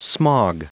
Prononciation du mot smog en anglais (fichier audio)
Prononciation du mot : smog